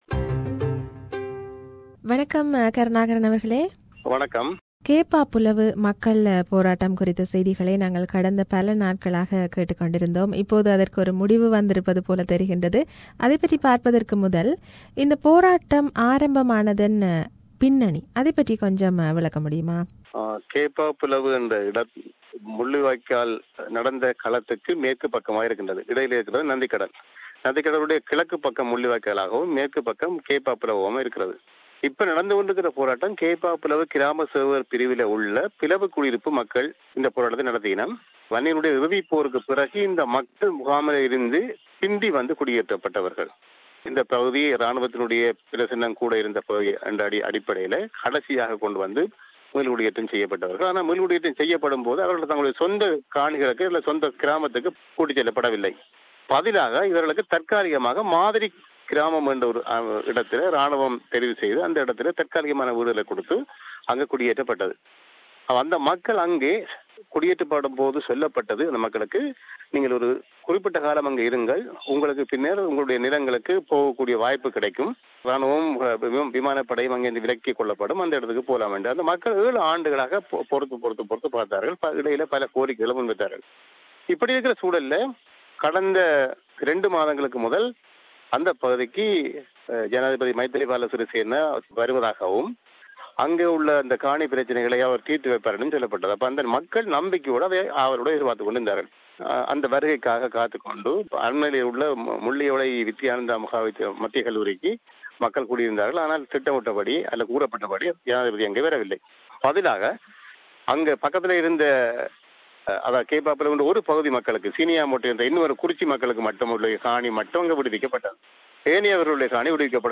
a leading journalist, analyses from Kilinochchi.